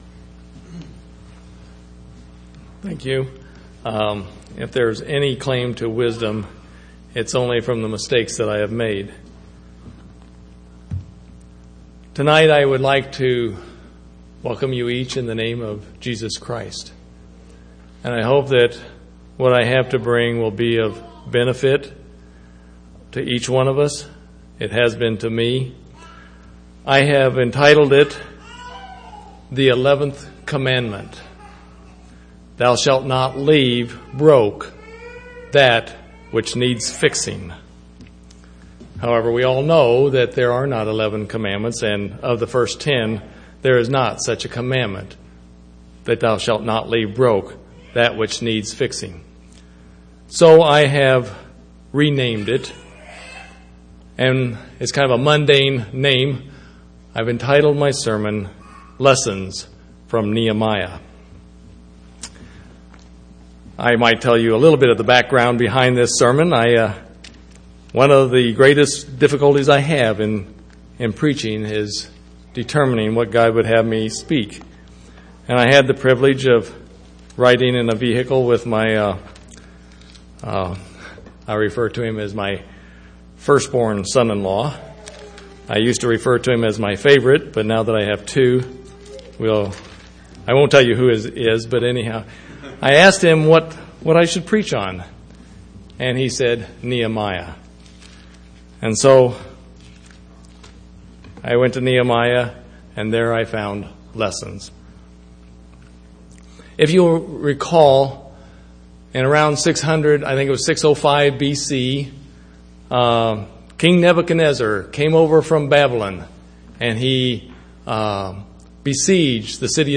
6/14/2009 Location: Temple Lot Local Event
audio-sermons